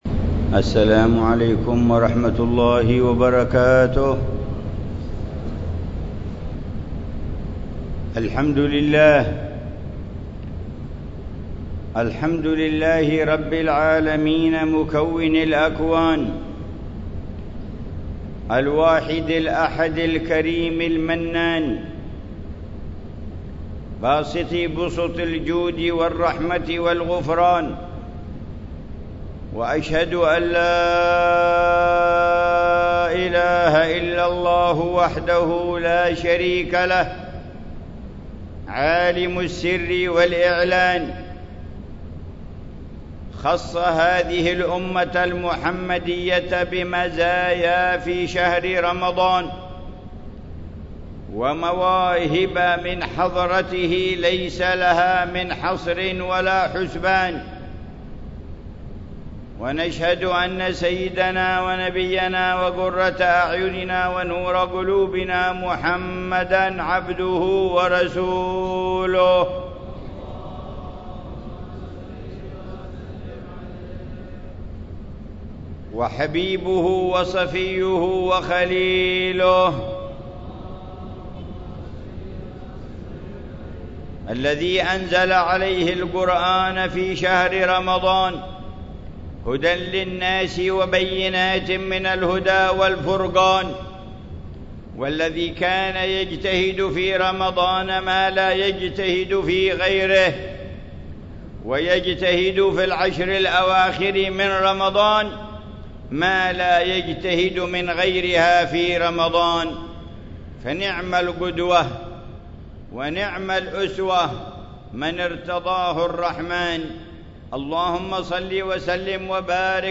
خطبة الجمعة للعلامة الحبيب عمر بن محمد بن حفيظ في جامع الإيمان بحارة عيديد، مدينة تريم، 18 رمضان 1442هـ بعنوان: